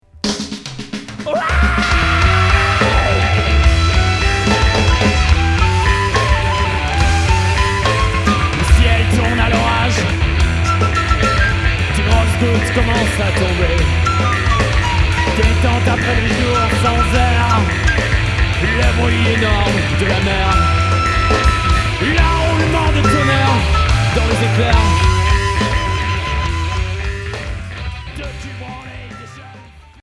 Emo core